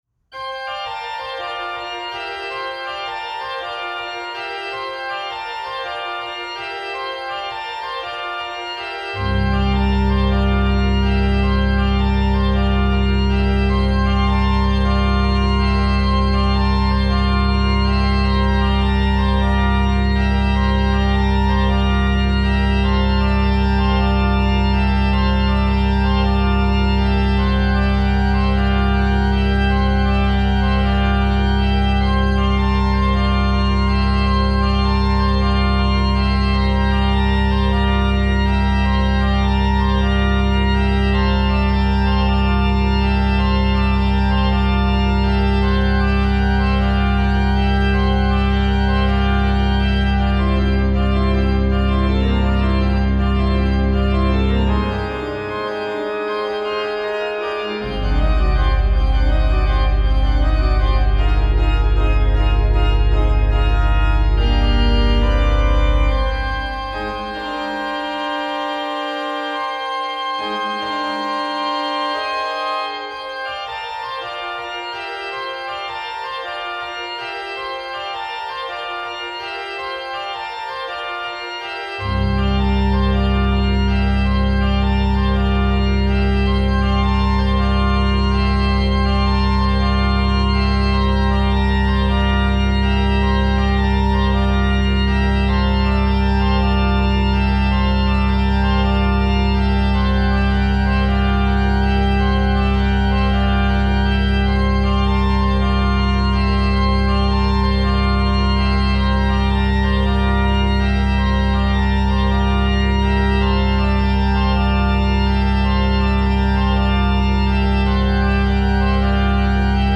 is a bright and vibrant piece